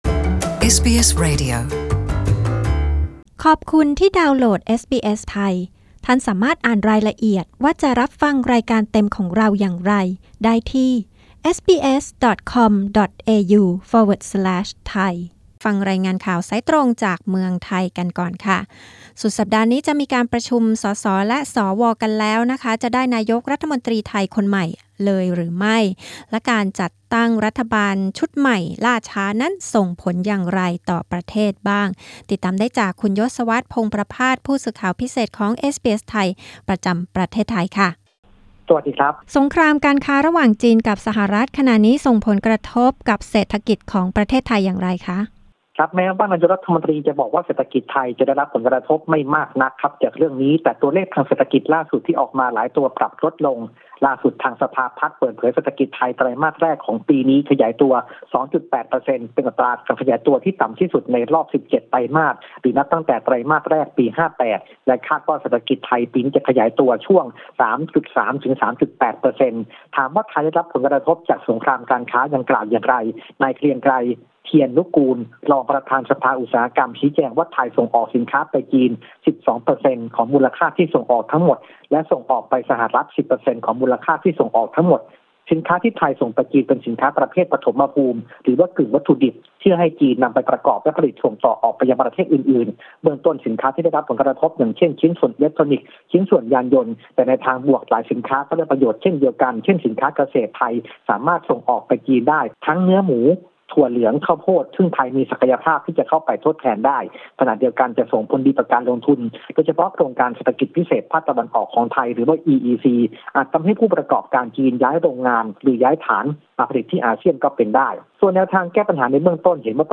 กดปุ่ม (▶) ด้านบนเพื่อฟังรายงานข่าว รายงานนี้ออกอากาศเมื่อคืนวันพฤหัสบดี ที่ 23 พ.ค. ในรายการวิทยุเอสบีเอส ไทย เวลา 22.00 น.